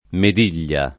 [ med & l’l’a ]